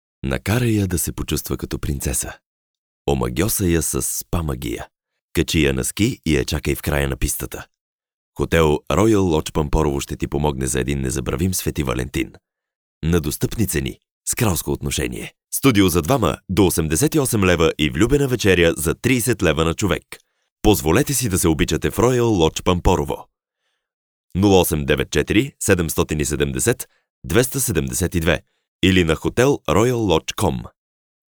Professioneller bulgarischer Sprecher für TV / Rundfunk / Industrie.
Sprechprobe: Industrie (Muttersprache):
bulgarian voice over artist